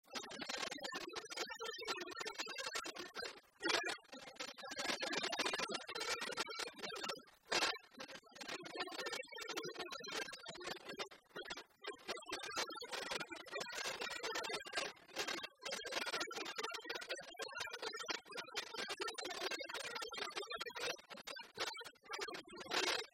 Château-d'Olonne (Le)
danse : quadrille : boulangère
Pièce musicale inédite